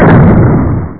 1 channel
boom.mp3